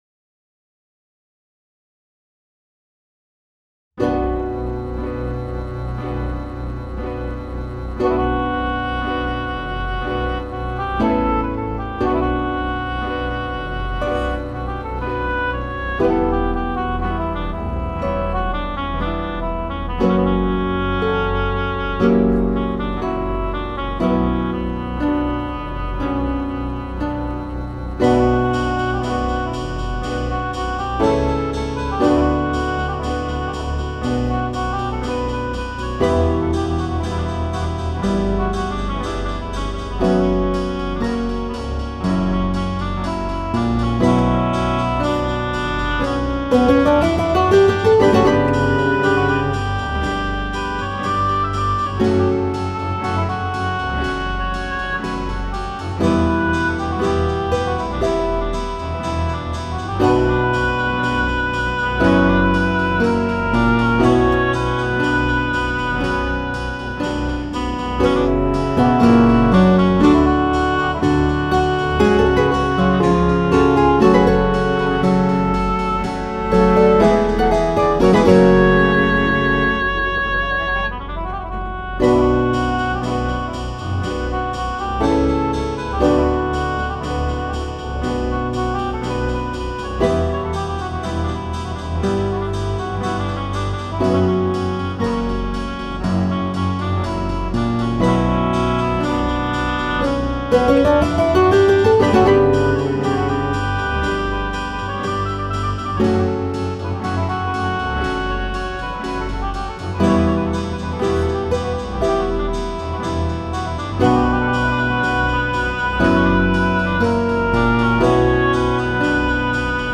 Hier kam für den Bass und die Gitarre das „12 Strings“ Preset zum Einsatz.